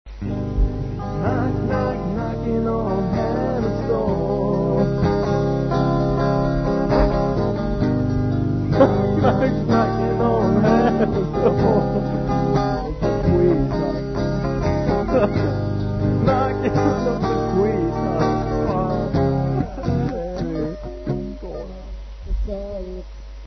Guitars, Vocals
Drums, Vocals, Bass